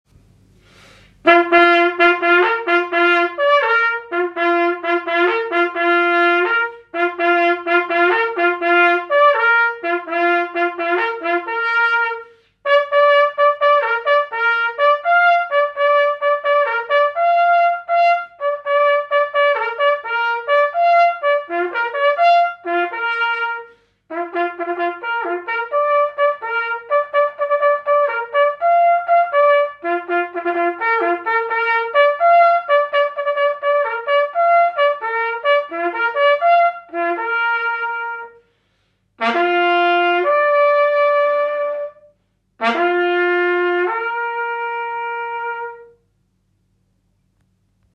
Herunder finder du samtlige gældende signaler til duelighedsblæsning og noder til DJ's officielle signaler.